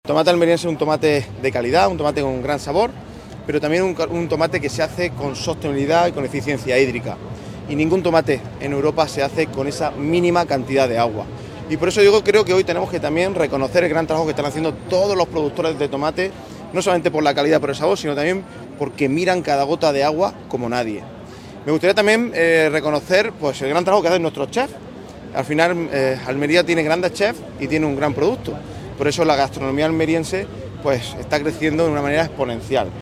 La alcaldesa, el presidente de Diputación y la delegada de la Junta asisten a la XII edición de un multitudinario evento cuya recaudación se destina a ANDA y Fundación Poco Frecuente
JOSE-ANTONIO-GARCIA-PRESIDENTE-DIPUTACION.mp3